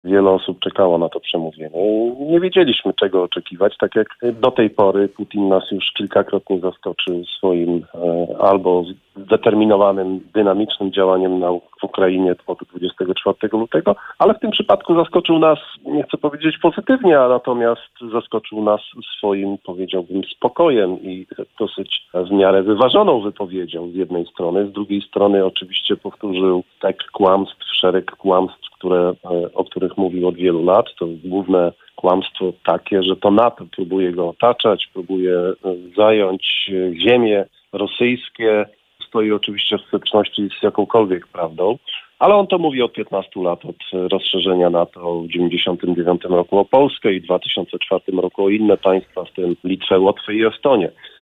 Putin nie ogłosił zakończenia wojny, jej zwycięstwa w wojnie czy powszechnej mobilizacji. Przemówienie prezydenta Rosji komentował w audycji „Poranny Gość” gen. Jarosław Stróżyk były zastępca dyrektora Zarządu Wywiadu sztabu wojskowego NATO, były attaché w USA.